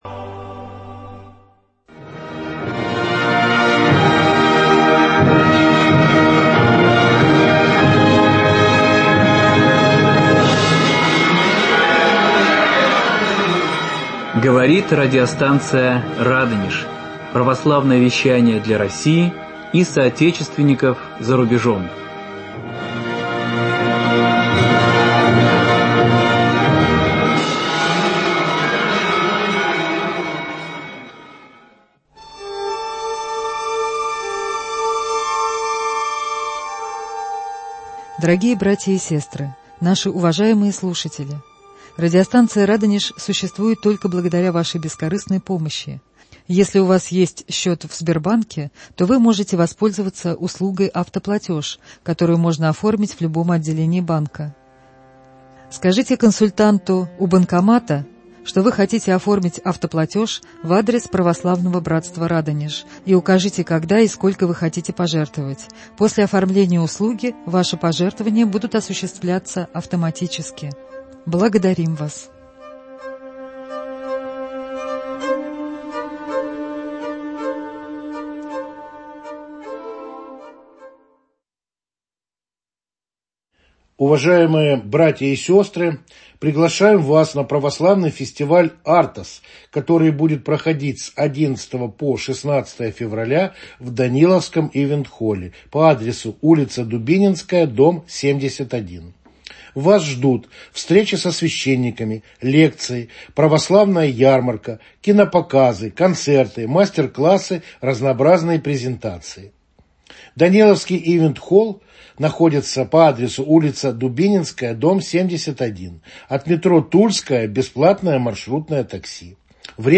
Ответы на вопросы радиослушателей.